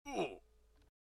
classic_hurt.ogg